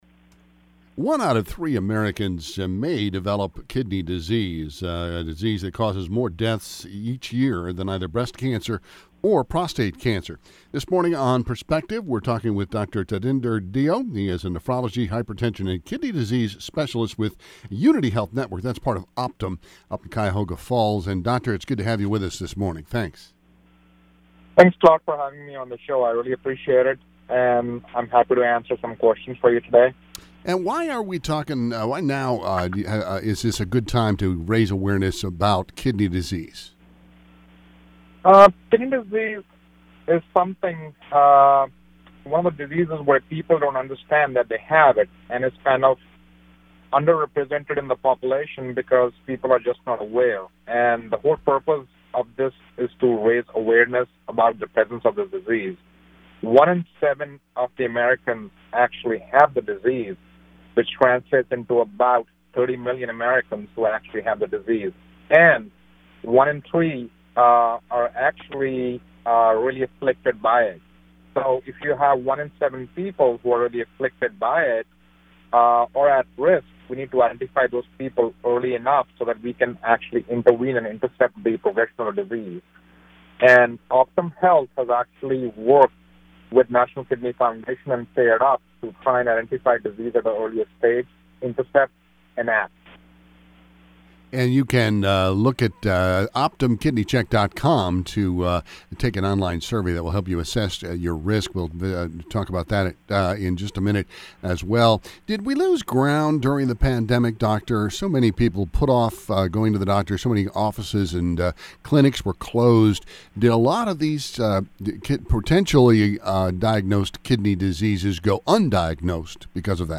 Promotes Kidney Health in Columbus Interview